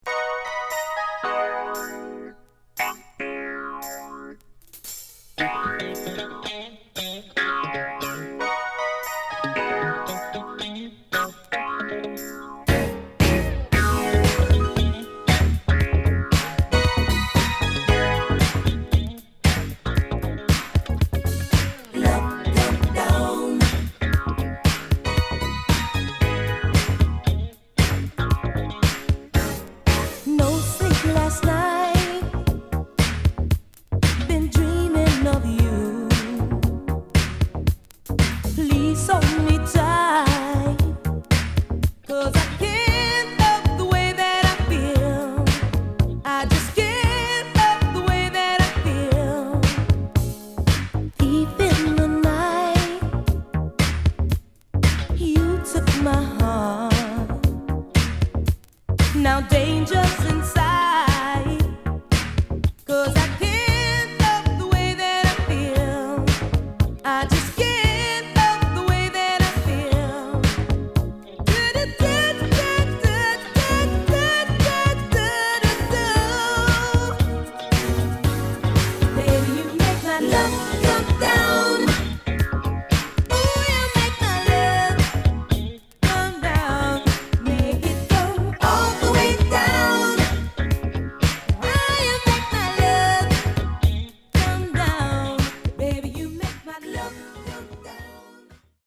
耳障りの良いメロディで、サンプリングからカヴァーまで数多く使われた名曲！